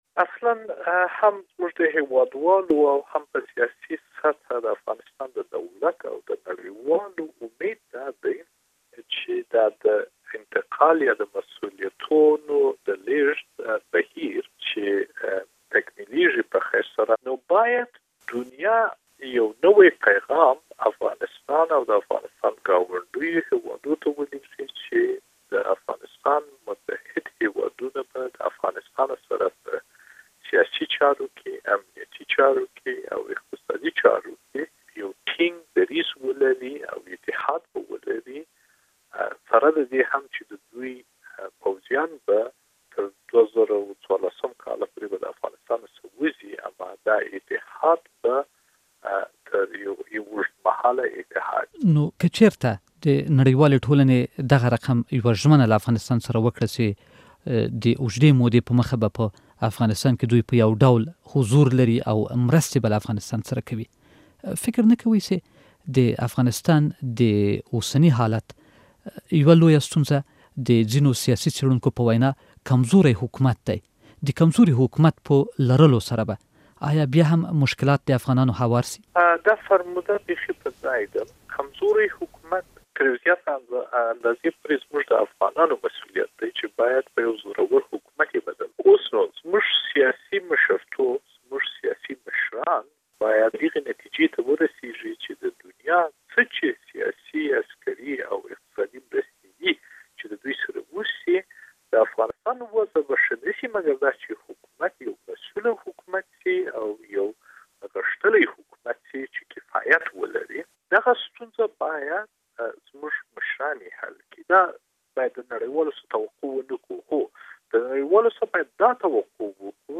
له حنیف اتمیر سره مرکه